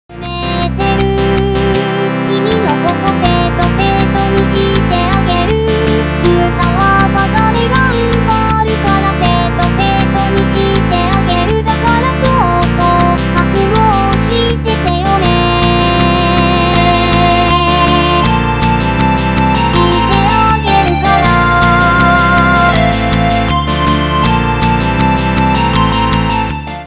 UTAU cover